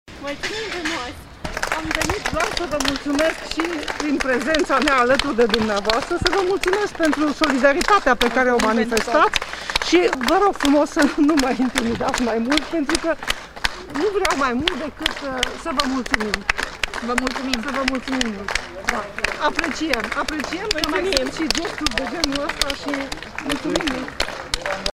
3.voxuri-flori-arad-9.mp3